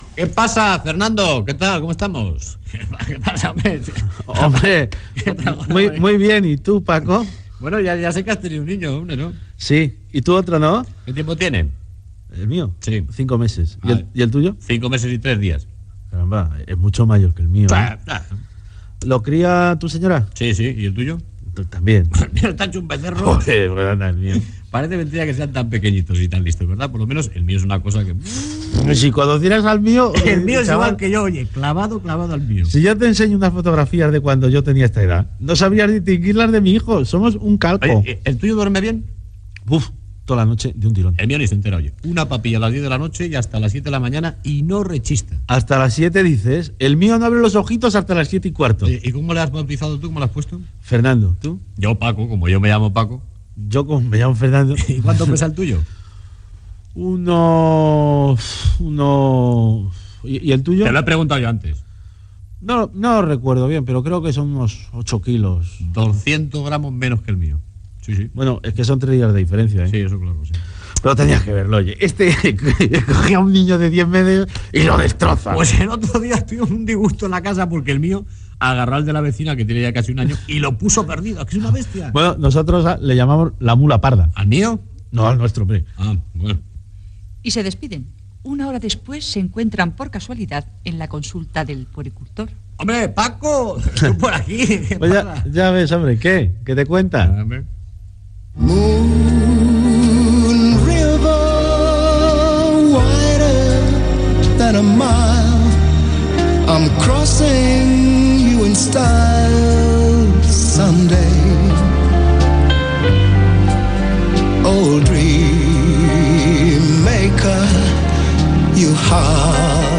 Esquetx "pares" i tema musical
Musical